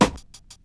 Royality free snare sample tuned to the G note. Loudest frequency: 2394Hz
• Old School Tight Low End Hip-Hop Snare Drum G# Key 78.wav
old-school-tight-low-end-hip-hop-snare-drum-g-sharp-key-78-GJO.wav